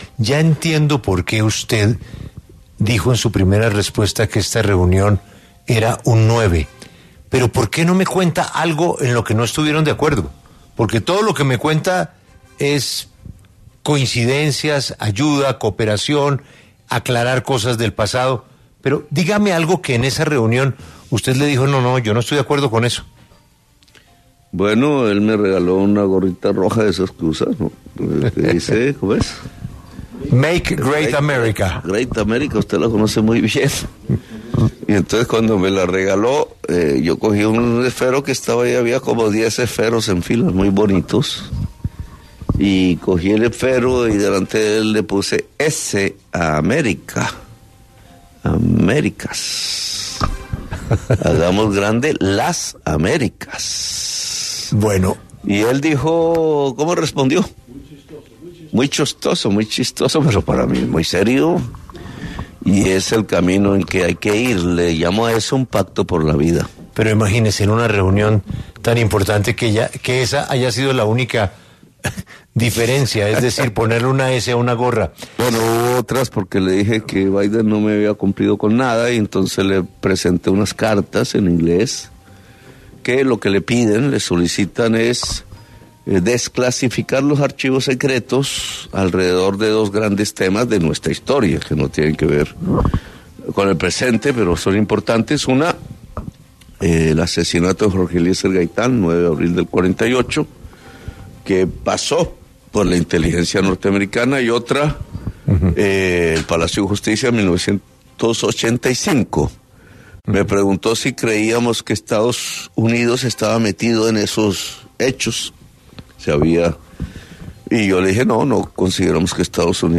En entrevista exclusiva con Caracol Radio y Julio Sánchez Cirsto, el presidente Gustavo Petro, reveló los primeros detalles de la reunión con su homólogo Donald Trump.